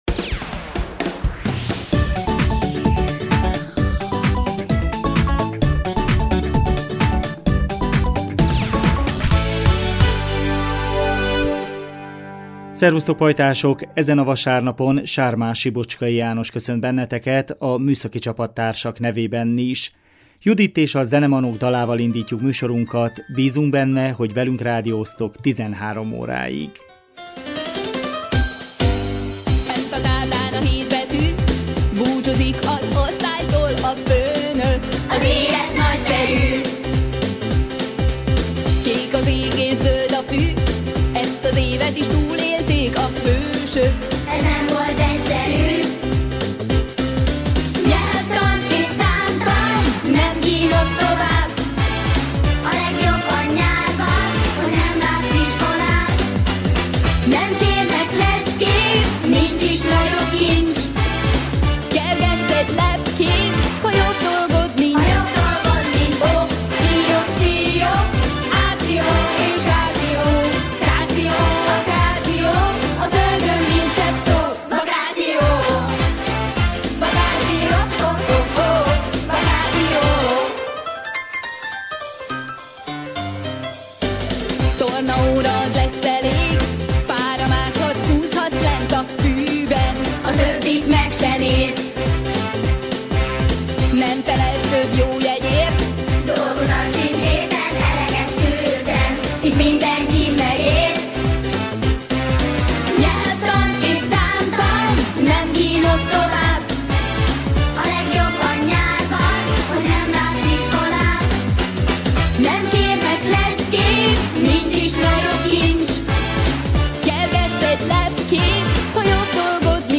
A 2019 június 16-án jelentkező műsor tartalma: A marosvásárhelyi Ariel Ifjúsági és Gyermekszínház júniusi kínálatában Méhes György – Murok Matyi című báb előadását hallhatjátok.